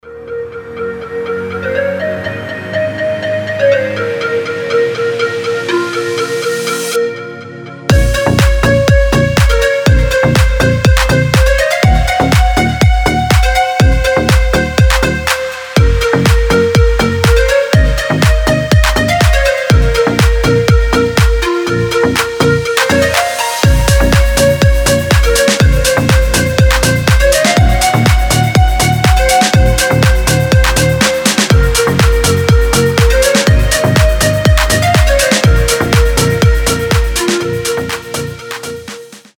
громкие
deep house
мелодичные
звонкие
Мелодичный рингтон со спокойным нарастанием в начале